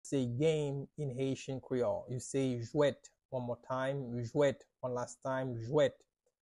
“Game” in Haitian Creole – “Jwèt” pronunciation by native Haitian Creole teacher
“Jwèt” Pronunciation in Haitian Creole by a native Haitian can be heard in the audio here or in the video below:
How-to-say-Game-in-Haitian-Creole-–-Jwet-by-native-Haitian-teacher.mp3